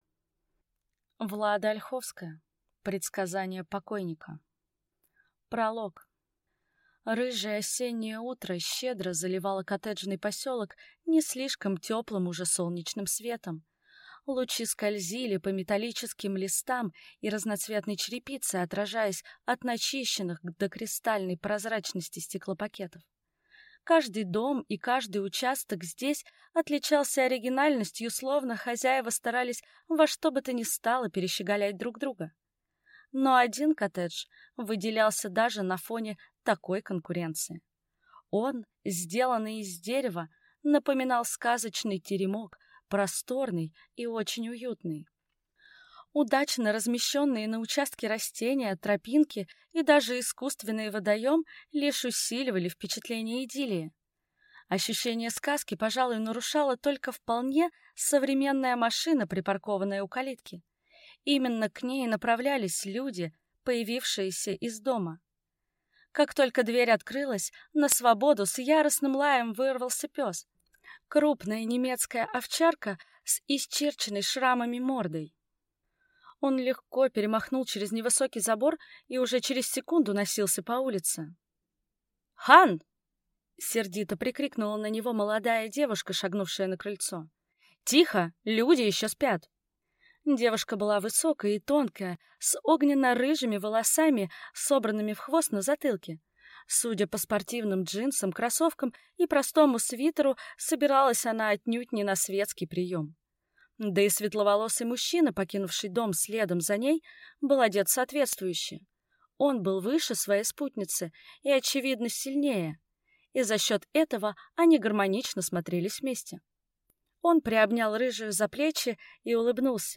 Аудиокнига Предсказания покойника | Библиотека аудиокниг
Прослушать и бесплатно скачать фрагмент аудиокниги